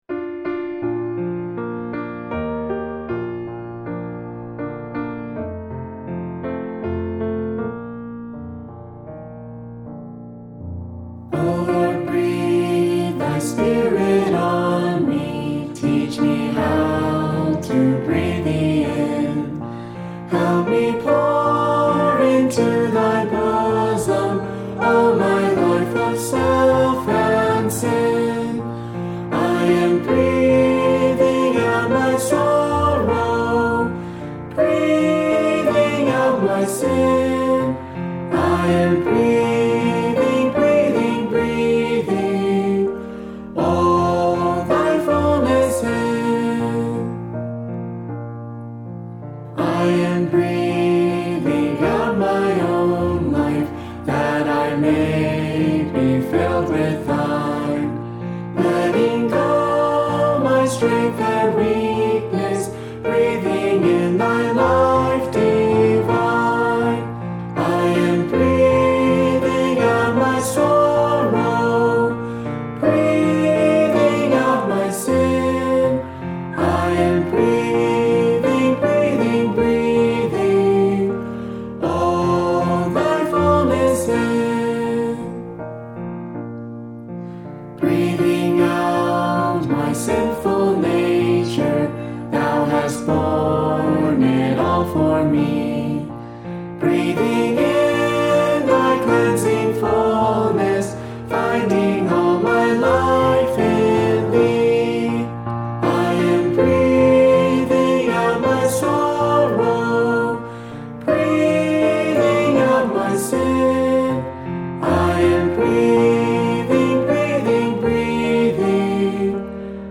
e0255_harmony.mp3